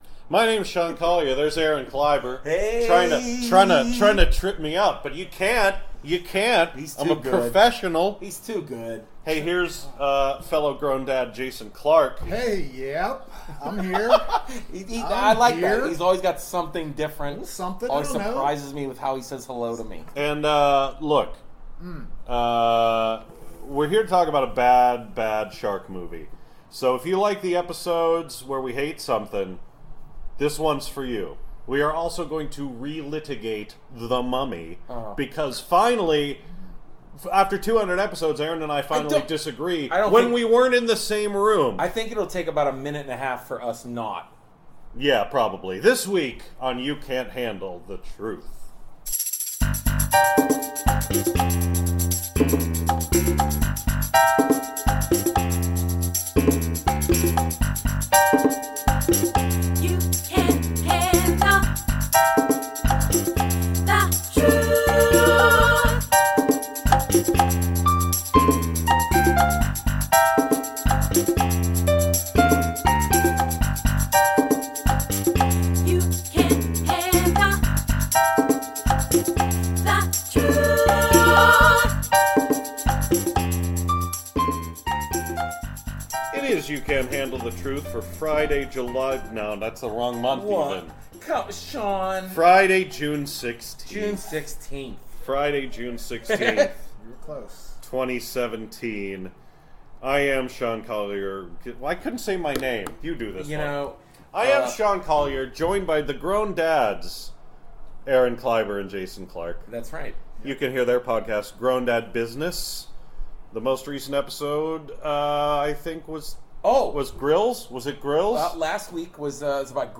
Every week we bring you reviews of movies, recorded immediately after an advance screening.